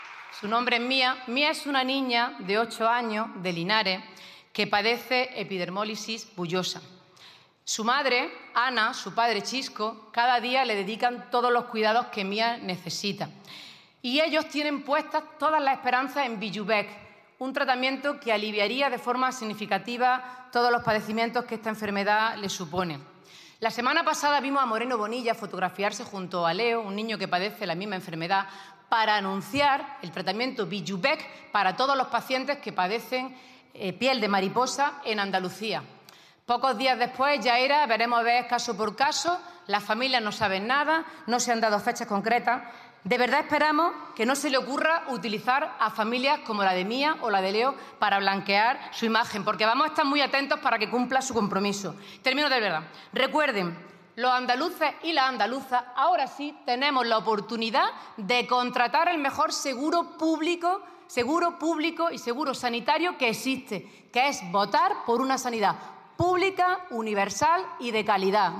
En una intervención en el Congreso sobre sanidad pública, Cobo acusó al PP de hundir la sanidad pública andaluza, “con 2 millones de pacientes en listas de espera, 1 millón de andaluces esperando para conocer un diagnóstico, 500.000 niños y niñas sin pediatra y entre 11 y 15 días de espera media para ser atendidos en Atención Primaria”.